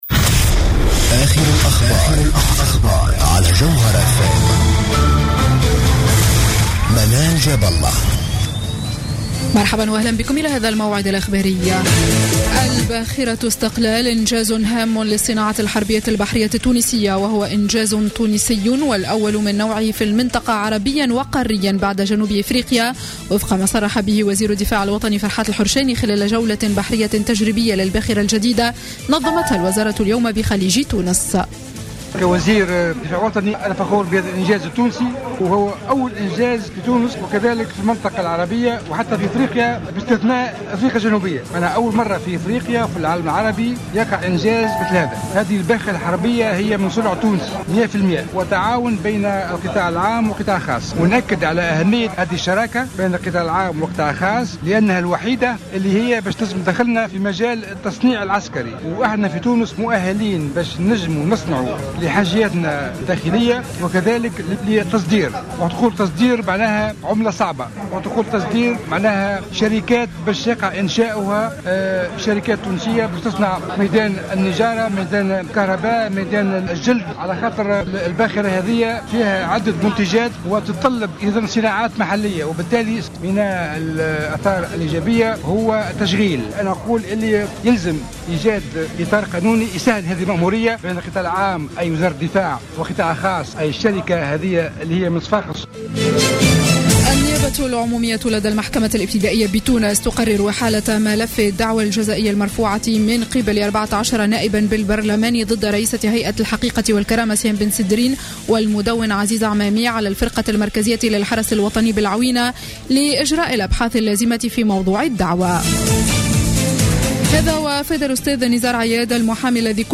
نشرة أخبار السابعة مساء ليوم الجمعة 21 أوت 2015